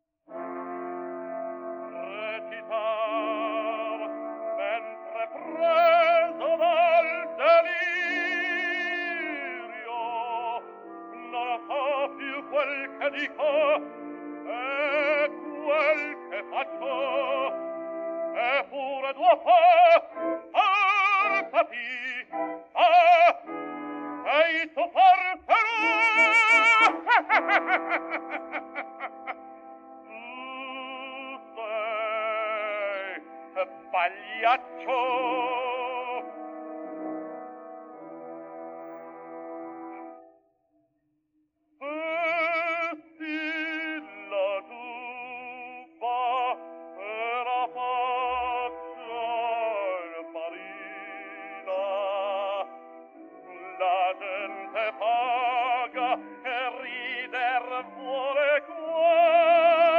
Index of /publications/papers/dafx-babe2/media/restored_recordings/caruso_giubba
denoised_cropped.wav